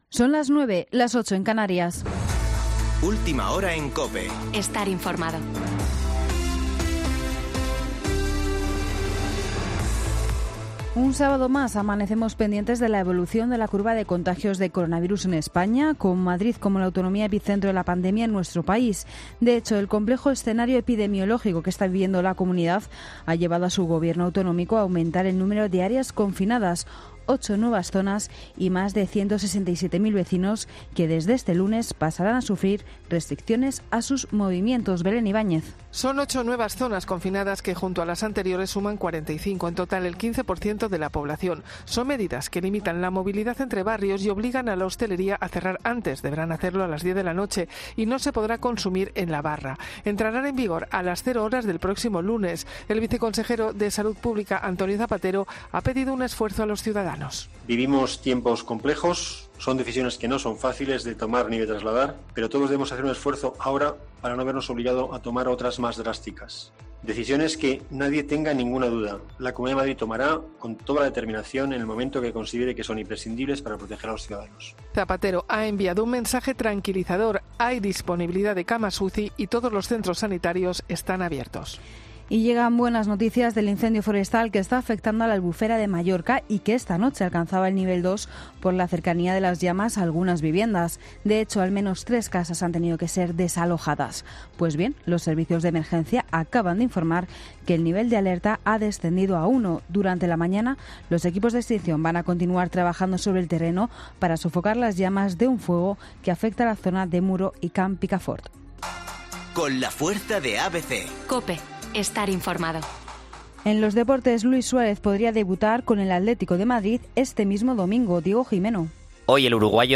Boletín de noticias de COPE del 26 de septiembre de 2020 a las 09.00 horas